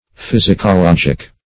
Search Result for " physicologic" : The Collaborative International Dictionary of English v.0.48: Physicologic \Phys`i*co*log"ic\, n. [Physico- + logic.] Logic illustrated by physics.